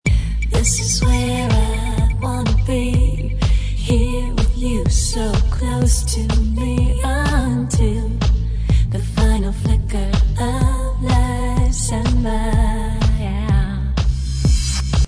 2-3 year old house with great vocal